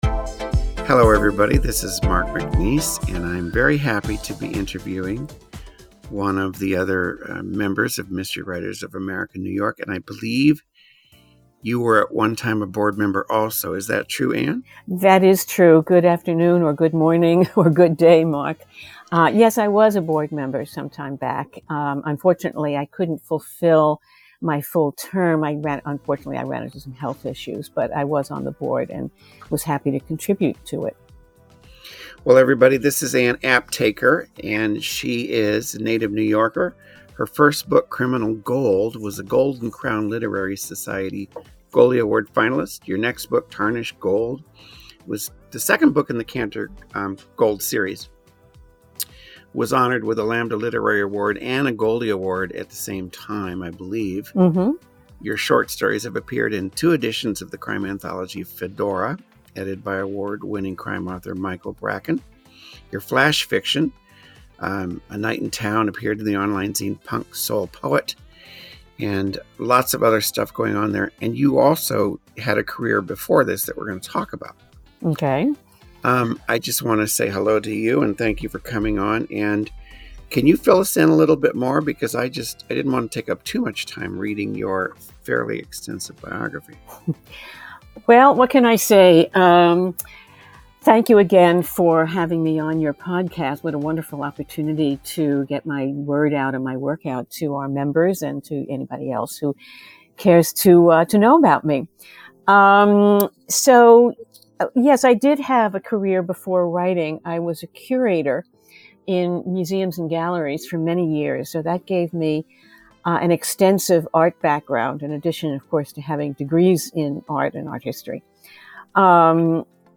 Fasten your headphones for another MWA-NY member interview.